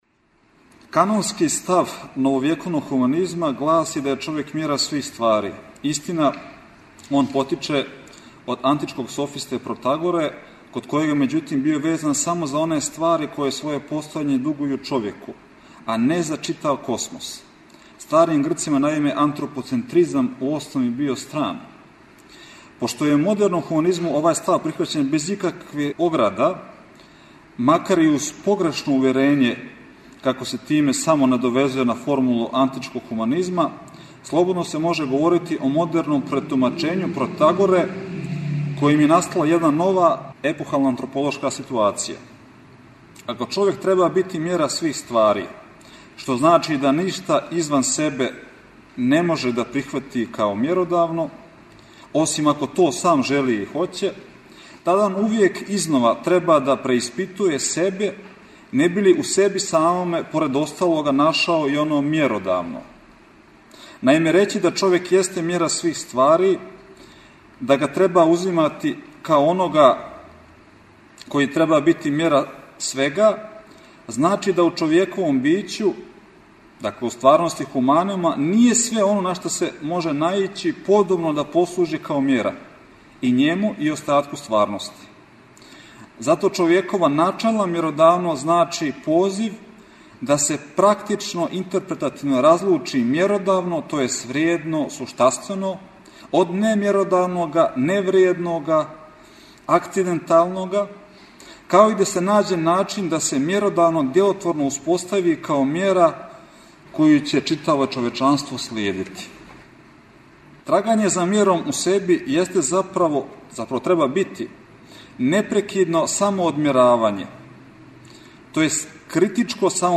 У циклусу НАУЧНИ СКУПОВИ четвртком емитујемо снимке са 27. Крушевачке филозофско-књижевне школе, чија тема је била Од корена до деоба – друштвена слика Србије у 20. веку.
У оквиру Видовданских свечаности града Крушевца 17. и 18. јуна у Културном центру Крушевац одржана је 27. Крушевачка филозофско-књижевна школа.